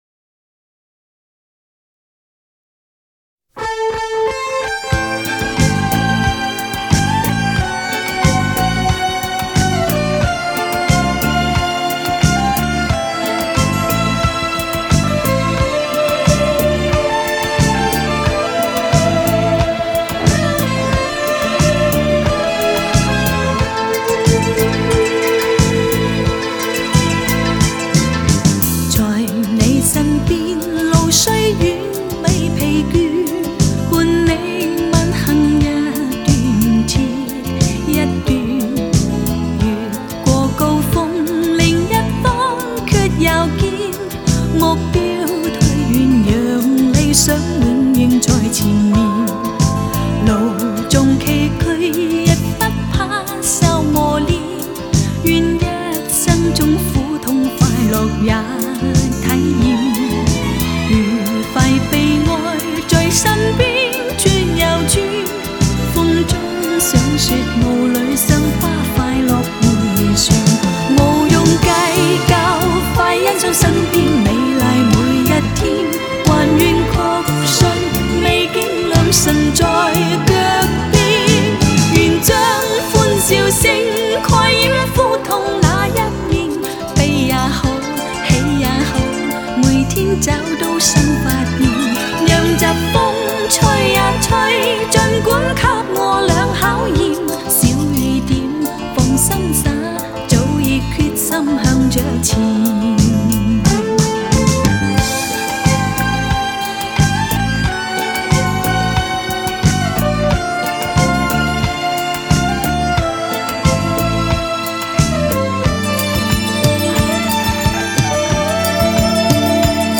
水晶般纯净的音质